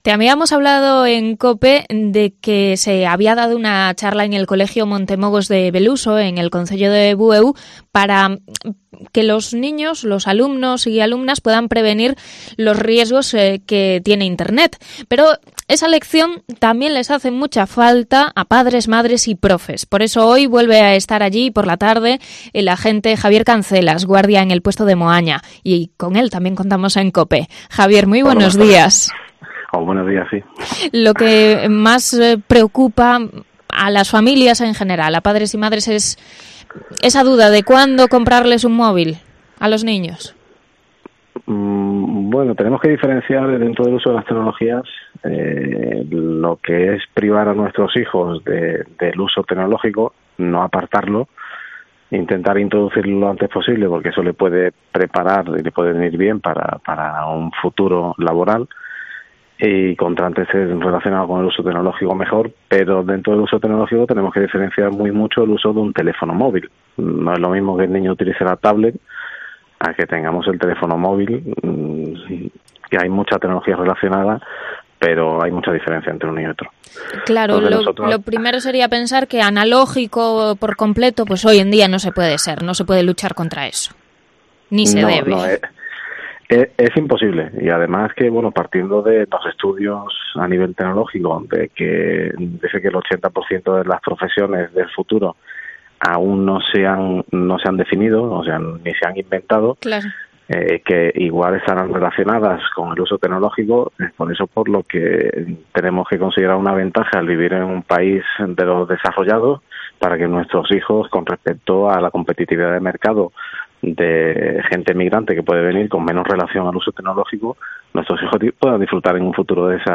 Entrevista sobre ciberseguridad